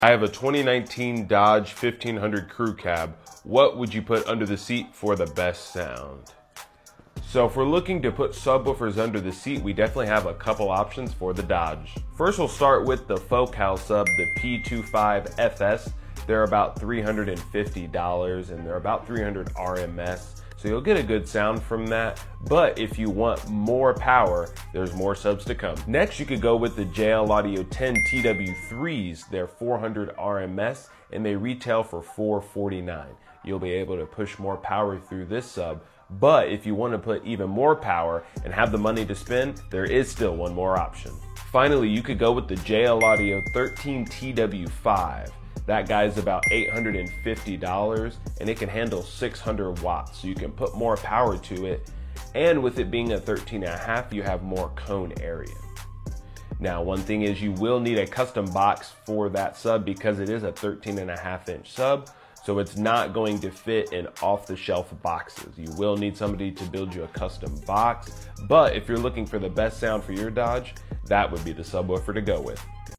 Best Car Audio Subwoofers For sound effects free download